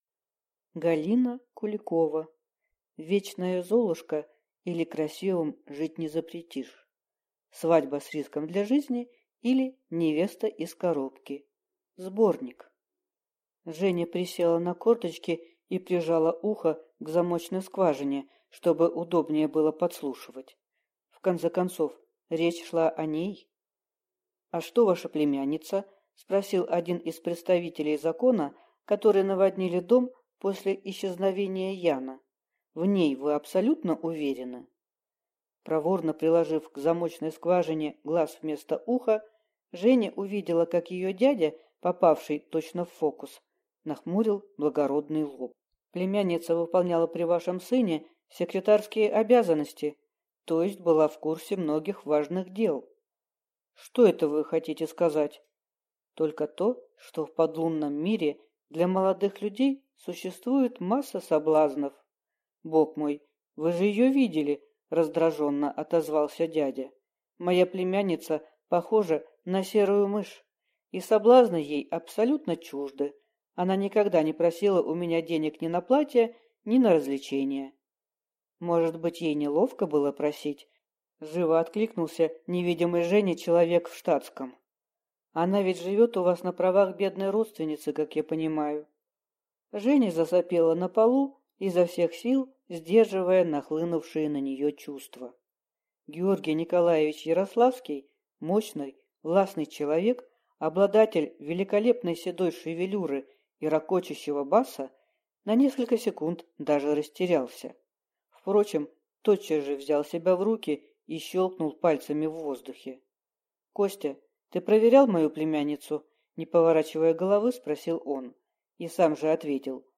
Аудиокнига Вечная Золушка, или Красивым жить не запретишь. Свадьба с риском для жизни, или Невеста из коробки (сборник) | Библиотека аудиокниг